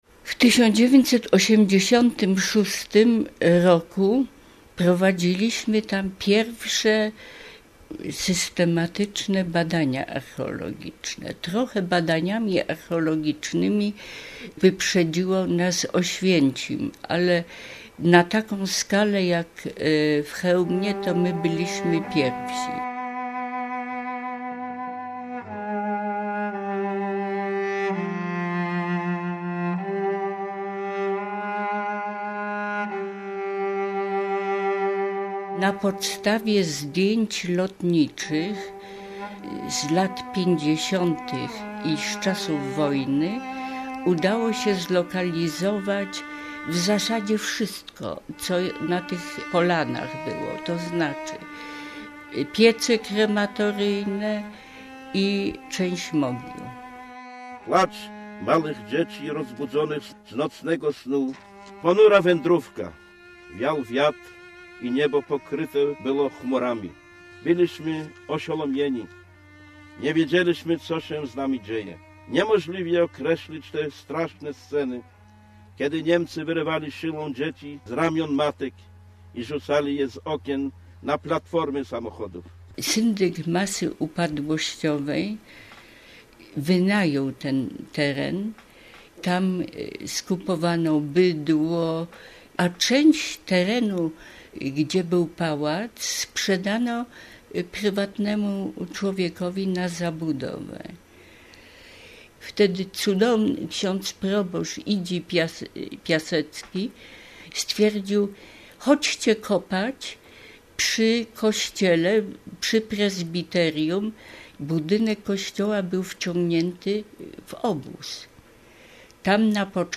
Na pewno nie było im łatwo - reportaż